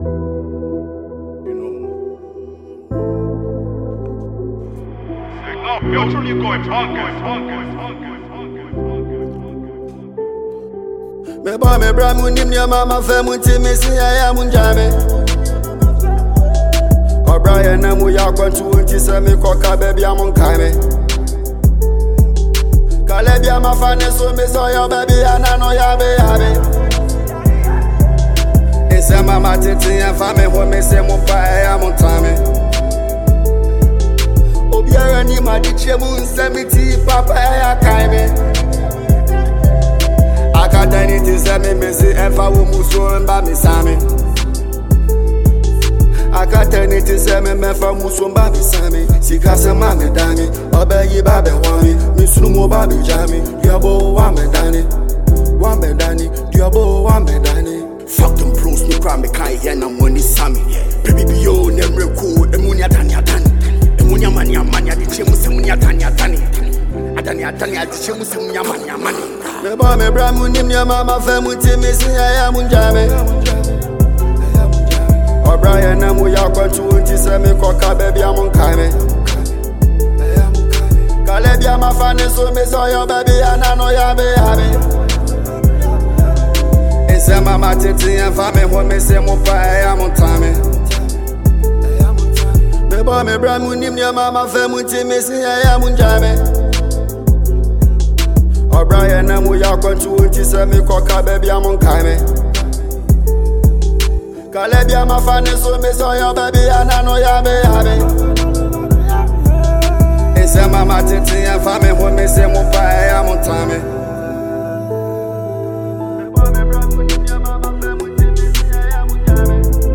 a Ghanaian trapper
asakaa tune